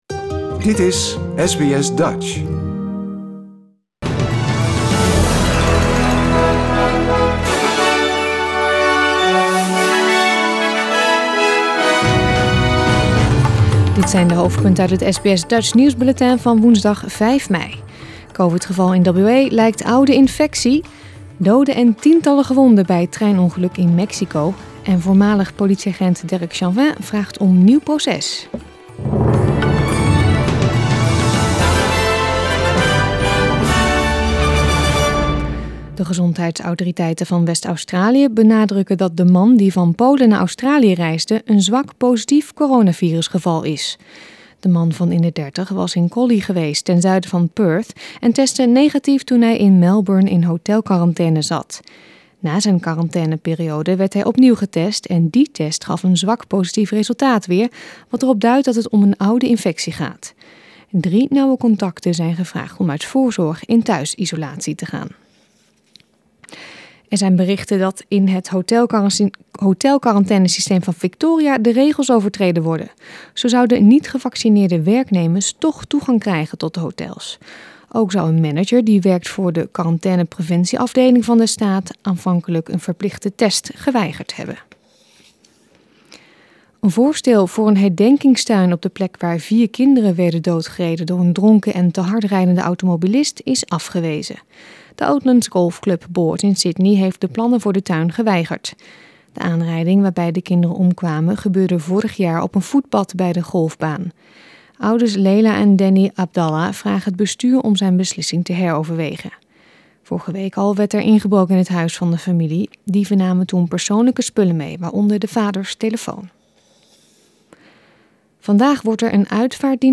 Nederlands/Australisch SBS Dutch nieuwsbulletin van woensdag 5 mei 2021
Beluister nu het Nederlands/Australisch SBS Dutch nieuwsbulletin uit de uitzending van woensdag 5 mei 2021